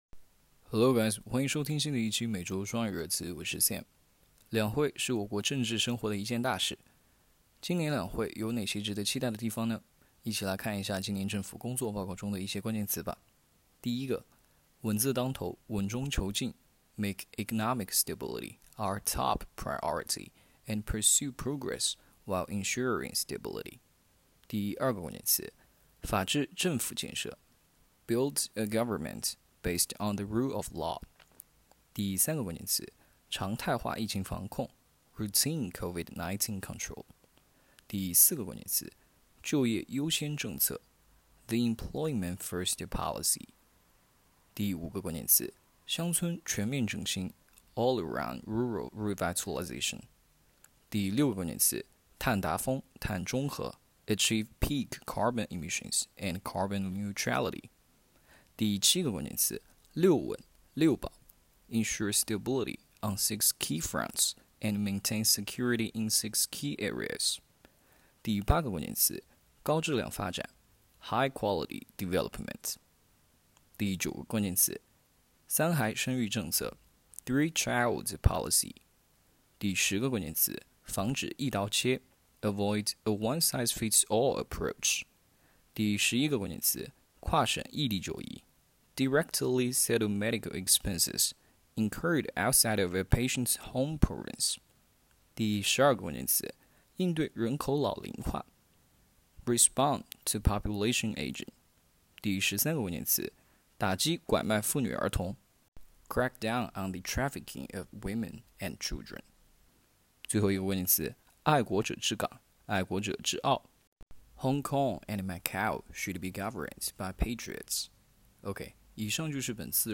双语有声热词